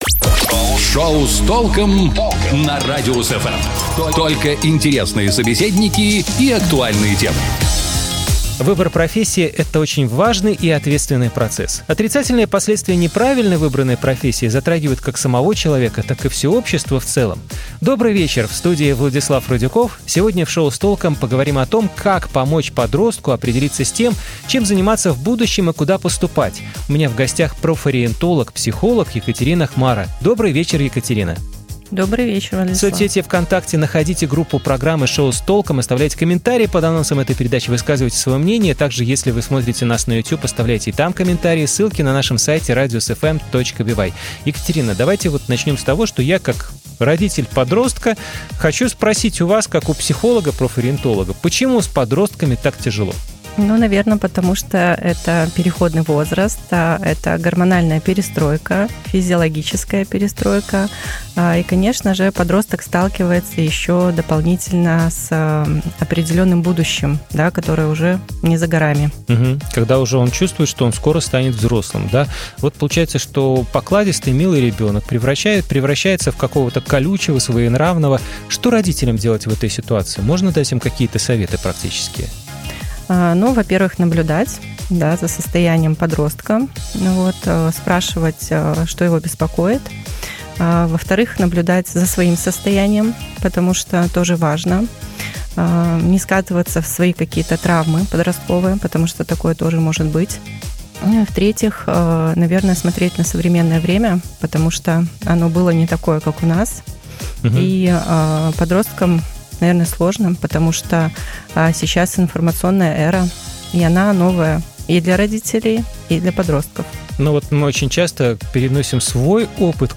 Сегодня в "Шоу с толком" – поговорим о том, как помочь подростку определиться с тем, чем заниматься в будущем и куда поступать? У нас в гостях профориентолог – психолог